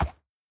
sounds / mob / horse / soft3.mp3